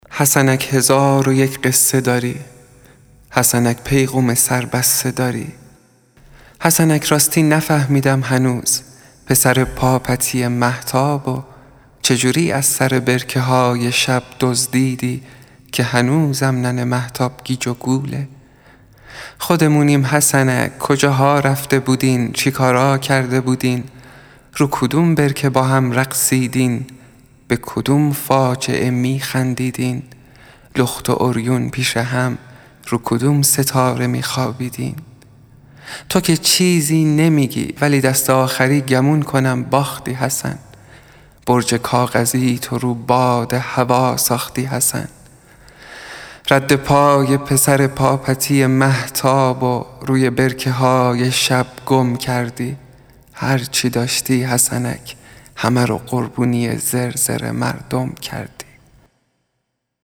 بخش ديگری از منظومه بلند «حسنک» با صدای شاعر